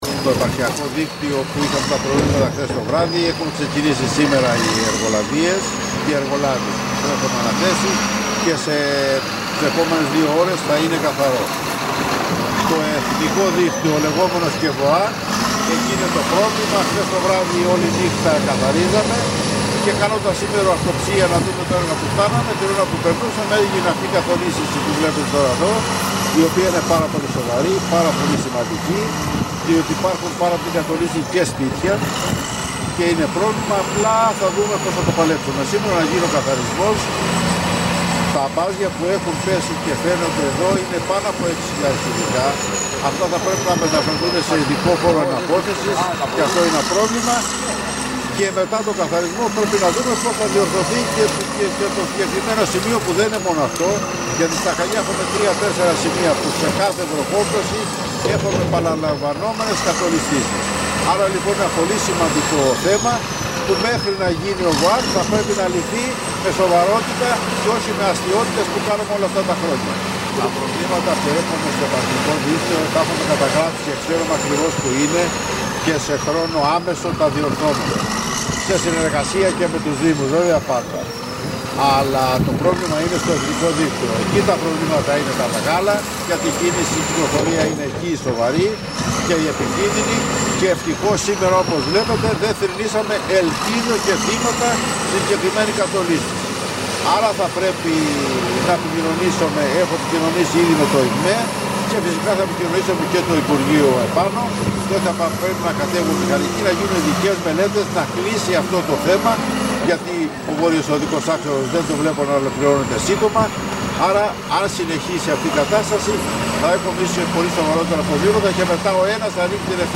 Ακούστε τις δηλώσεις του Αντιπεριφερειάρχη Χανίων, Απόστολου Βουλγαράκη:
ΒΟΥΛΓΑΡΑΚΗΣ-ΑΠΟΣΤΟΛΟΣ-Αντιπεριφερειάρχης-Χανίων.mp3